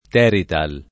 terital
[ t $ rital o terit # l ]